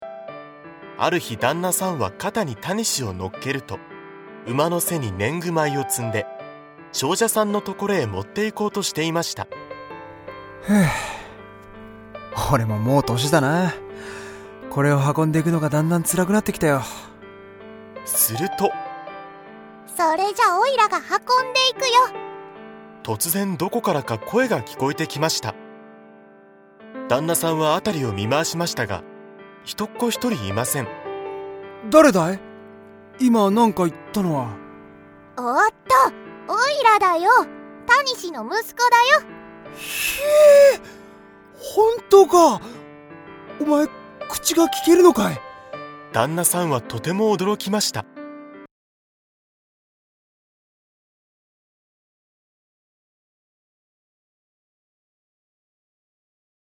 [オーディオブック] たにし長者 世界の童話シリーズその53
大人も子どもも一緒になって、多彩なキャストと、楽しい音楽でお楽しみ下さい。
大人も子供も楽しめる童話オーディオブックを、多彩なキャストとBGMでお届けします。